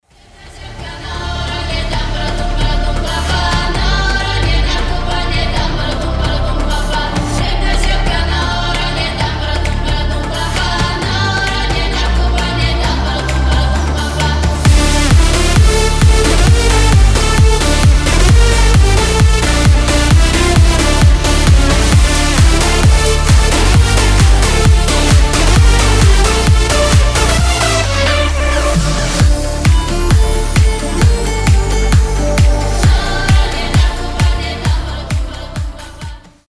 Похоже на болгарскую песню, хоть и музыканты греческие